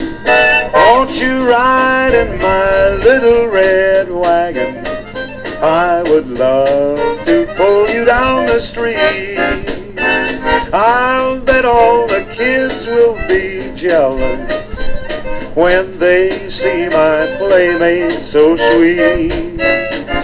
Country/Western Swing singer